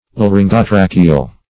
Search Result for " laryngotracheal" : The Collaborative International Dictionary of English v.0.48: Laryngotracheal \La*ryn`go*tra"che*al\, a. [Larynx + tracheal.]